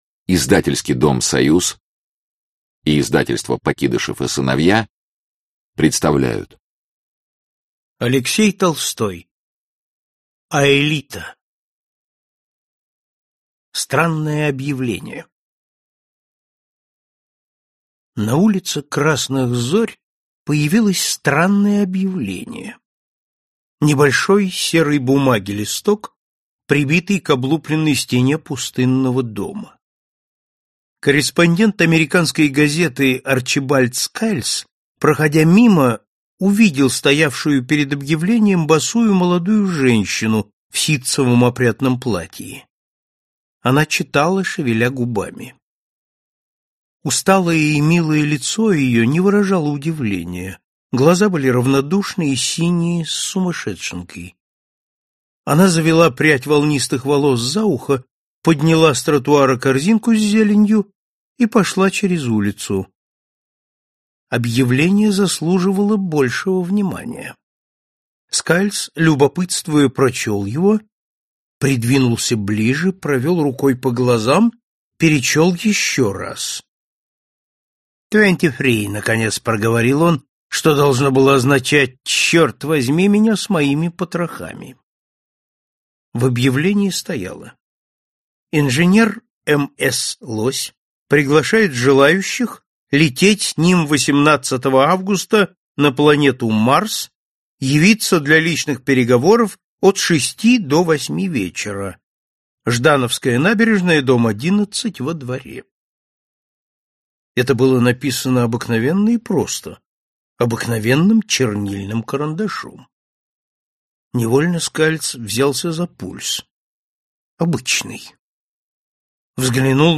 Аудиокнига Аэлита | Библиотека аудиокниг